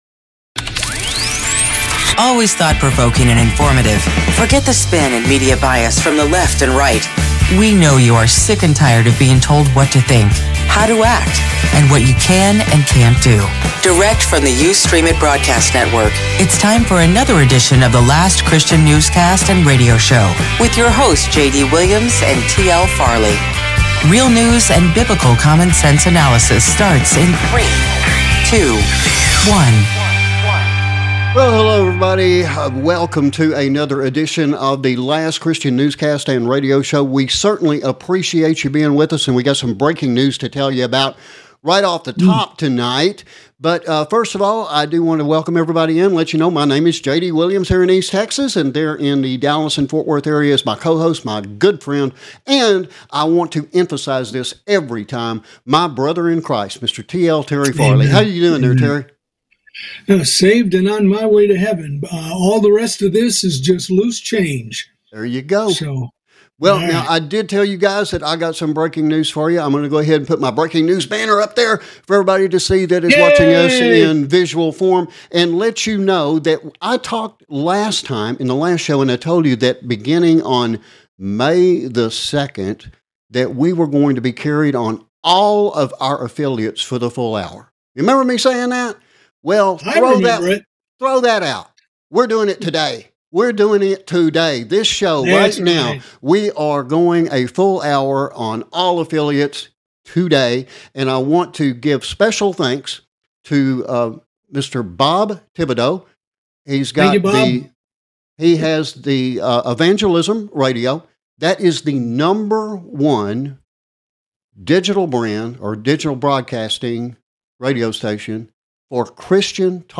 Current Events and News from a Christian Perspective We continue to examine Matthew 24 from the Perspective of Israel.